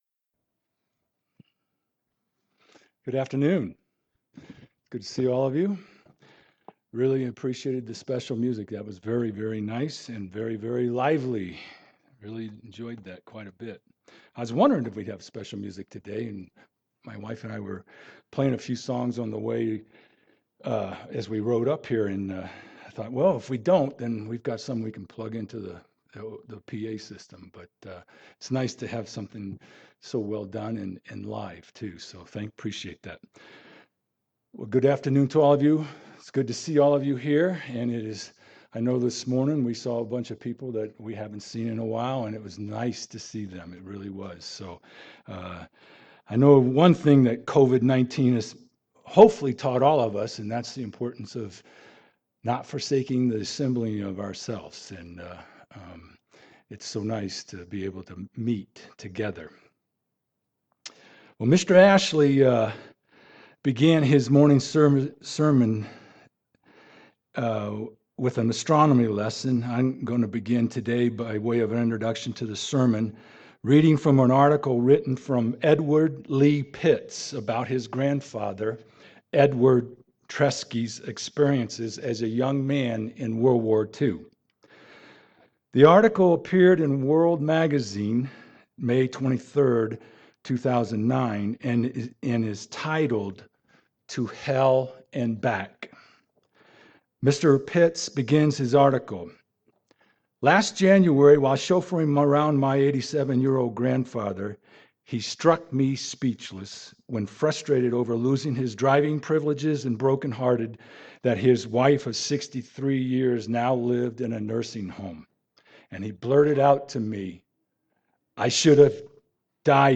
What does the Bible tells us about this time and the cause of it? This sermon explores the scriptures for answers to those questions.